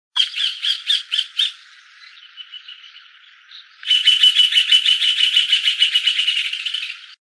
Rufous Hornero (Furnarius rufus)
Sex: Both
Life Stage: Adult
Location or protected area: Reserva Ecológica Costanera Sur (RECS)
Condition: Wild
Certainty: Recorded vocal